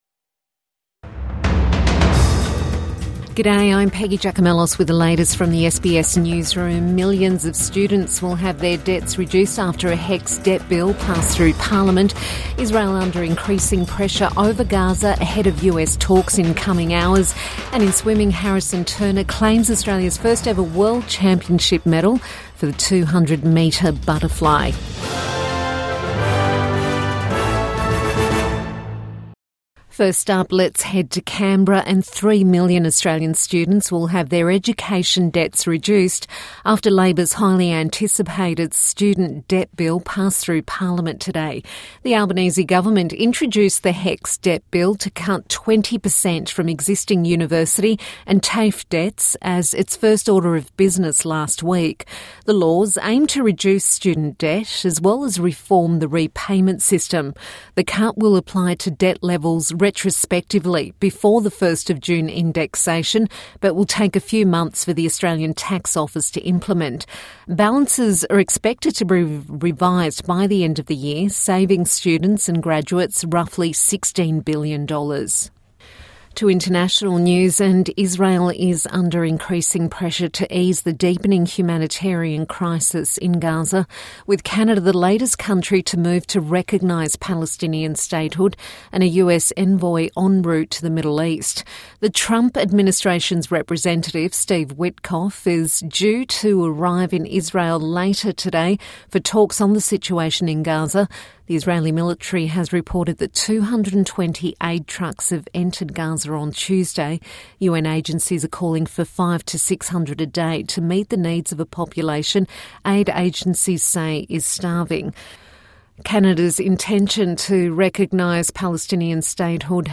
HECS debt bill passes through parliament | Midday News Bulletin 31 July 2025